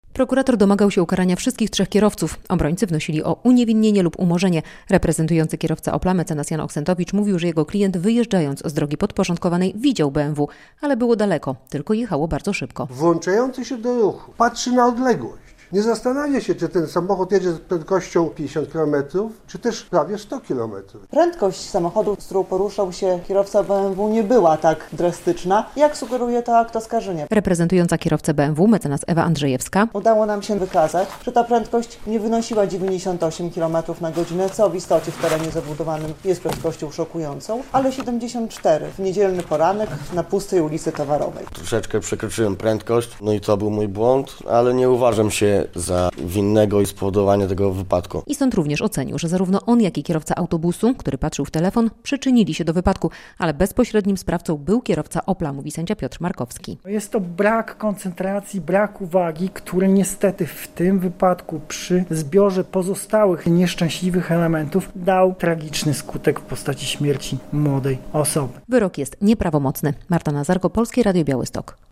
Skazanie i dwa umorzenia w procesie trzech kierowców ws. śmiertelnego wypadku - relacja